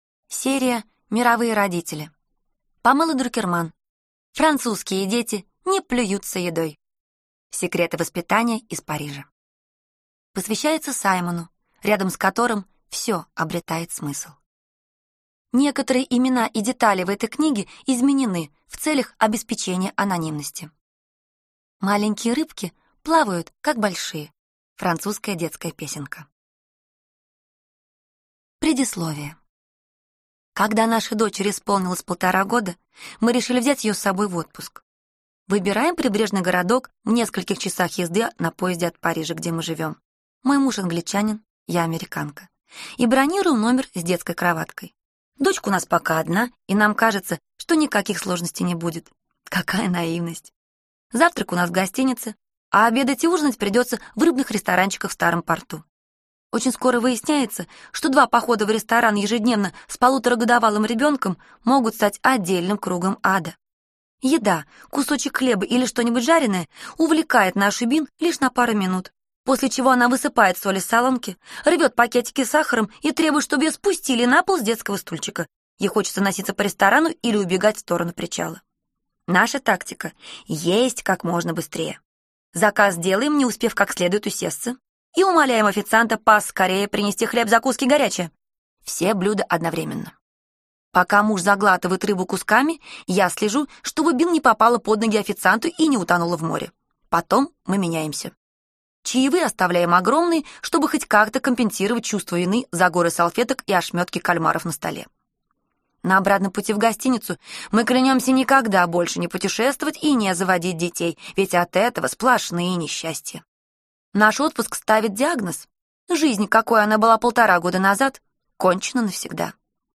| Обзор книги Французские дети не плюются едой | Памела Друкерман | Что читать?